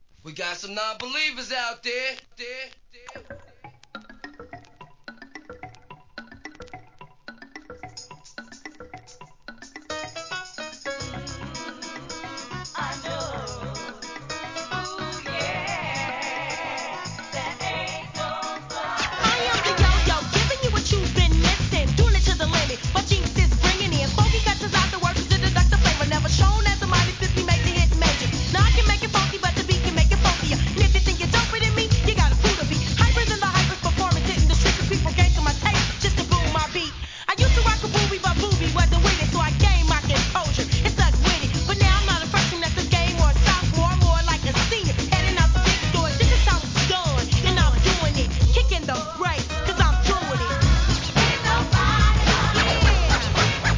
HIP HOP/R&B
イケイケのFUNKYトラックでの1991年作品!!